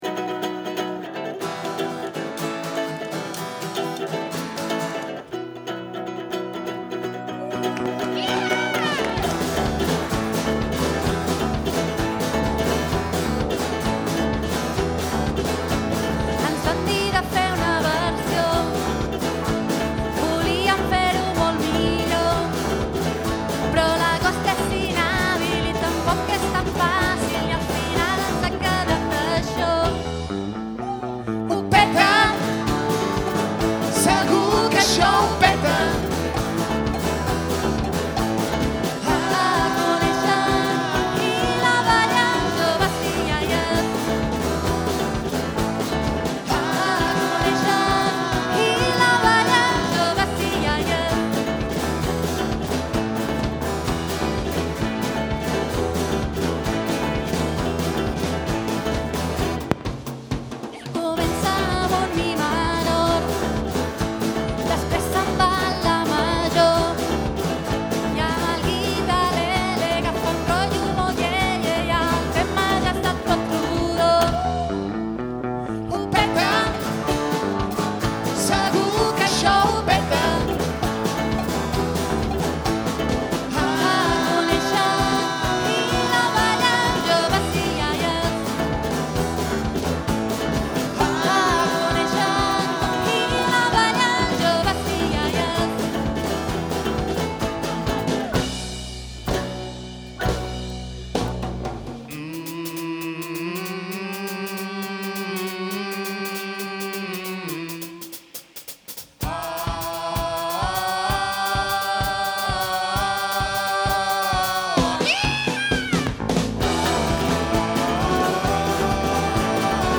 directe